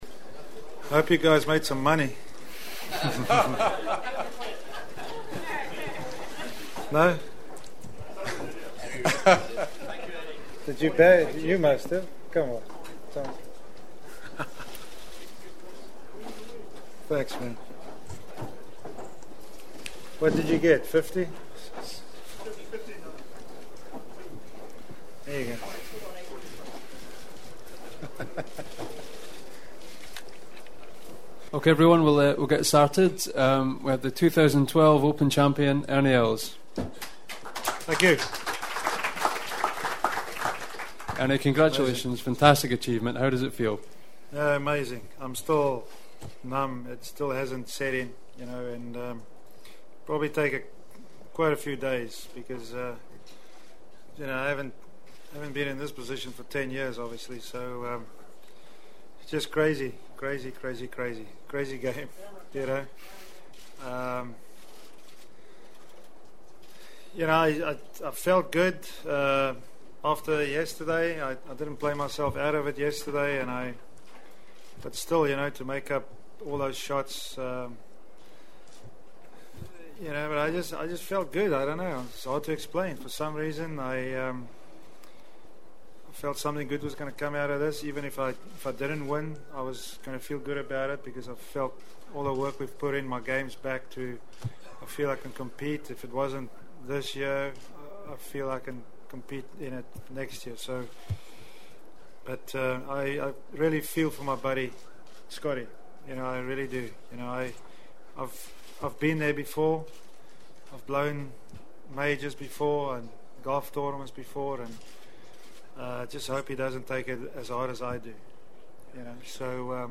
And finally I got to be the first to ask Els what it’s like to win The Open again while doing it in the face of his friend who let it get away? But first Ernie and I joked a bit about his long odds of winning this one…